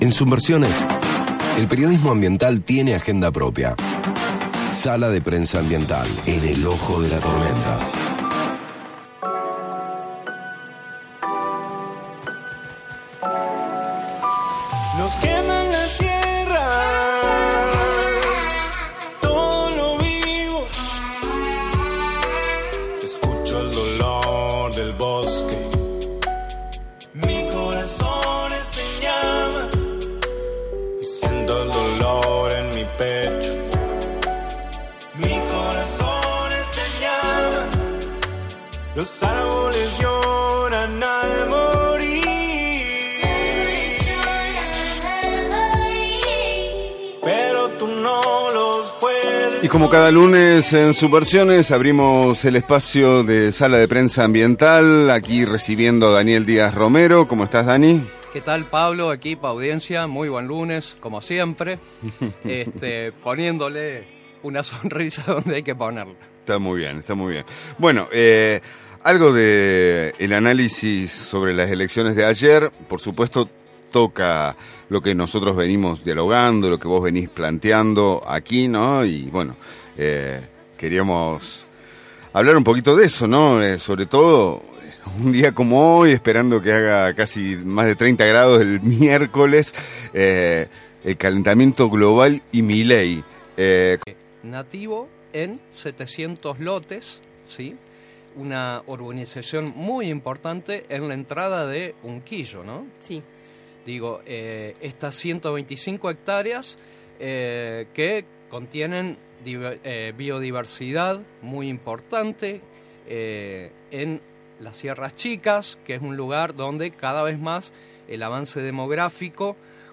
El Montecito de Unquillo | Sala de Prensa Ambiental